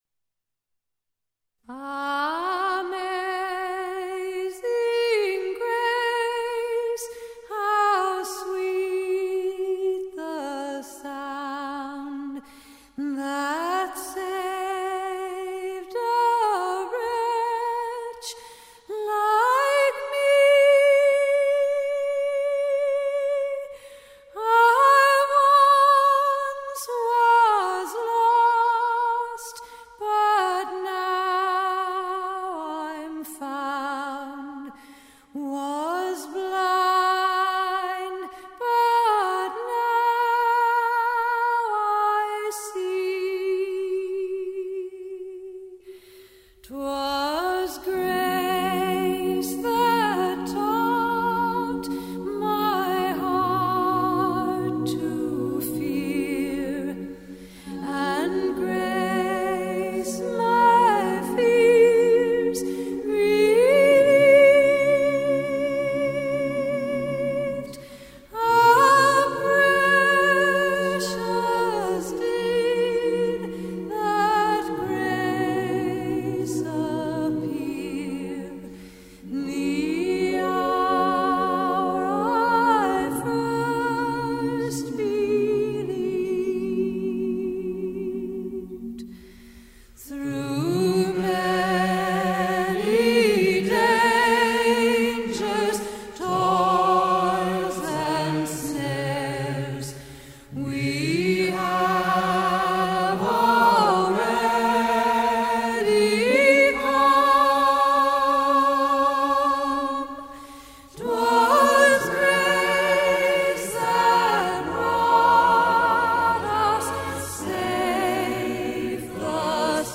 这首无伴奏歌曲的绝美程度，实在是文字难以企及。